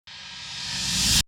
Reverse.wav